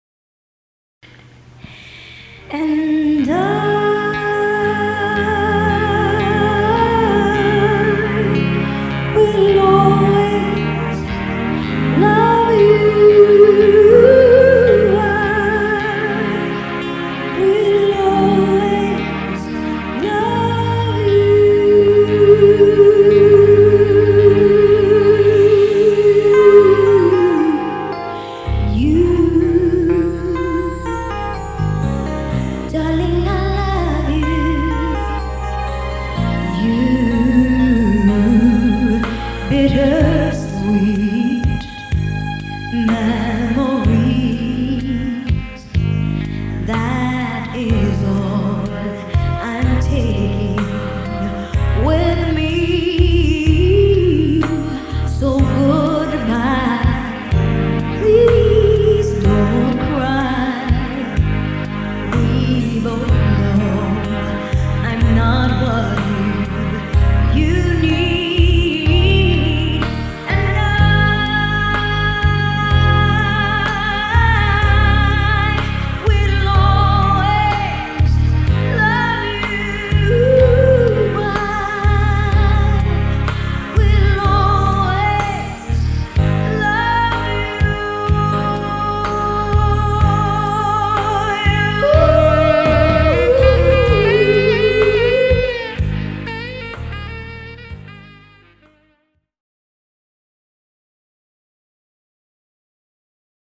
Wedding Singer